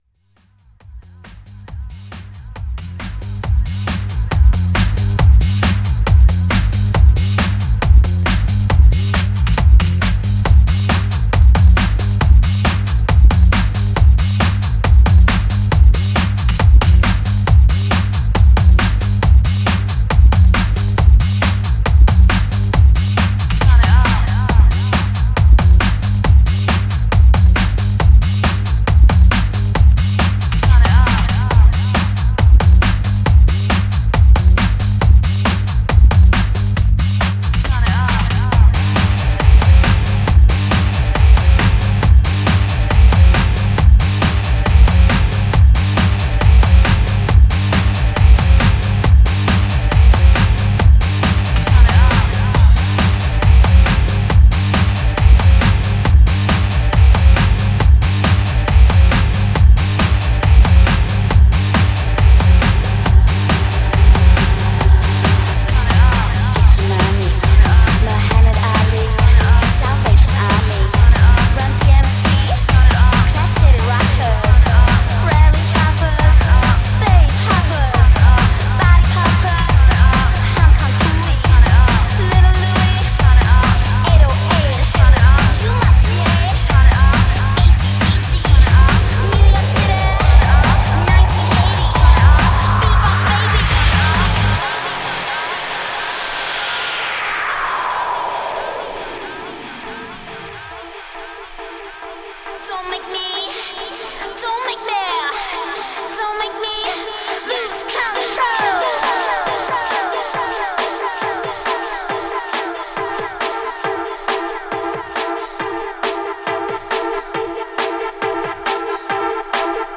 Styl: Breaks/Breakbeat
Vocal Mix